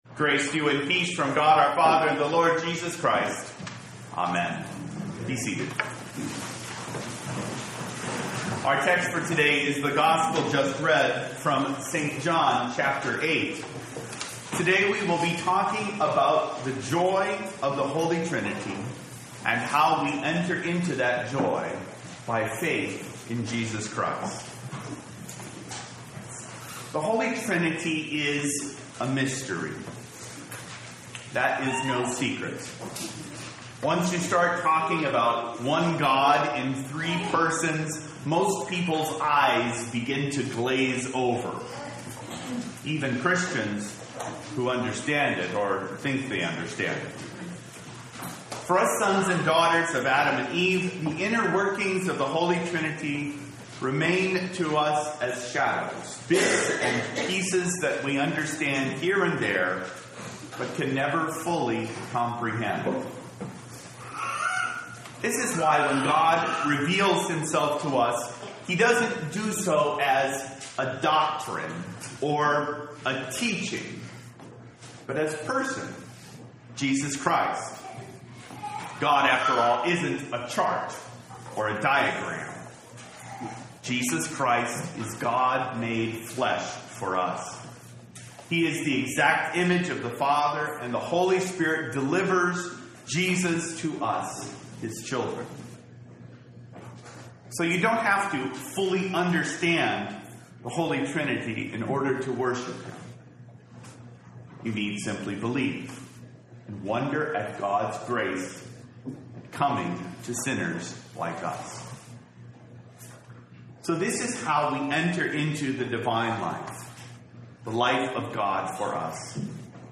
Holy Cross Lutheran Church